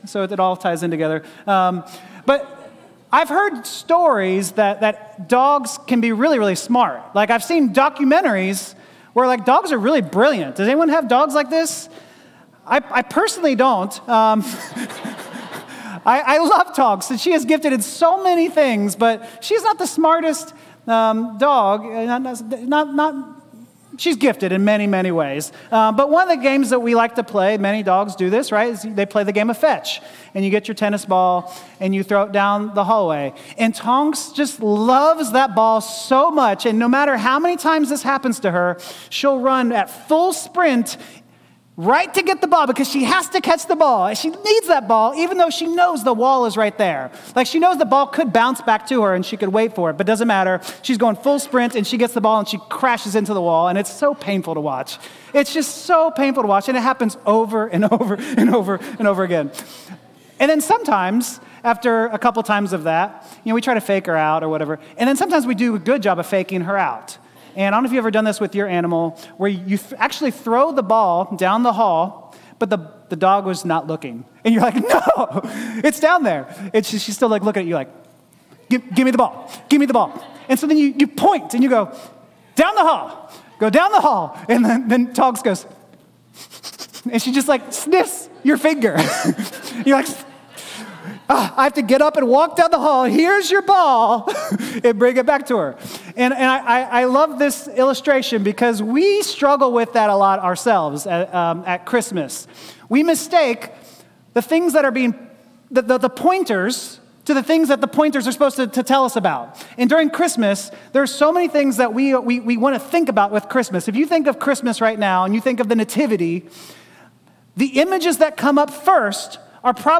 Christmas Eve Service | 5 PM Service Dec 24th 2025